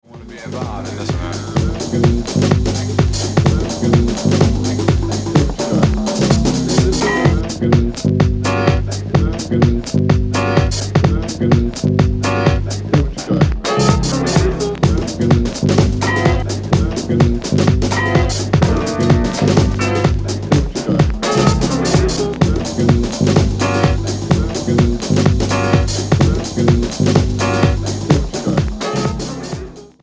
Broken Beat / Nu Jazz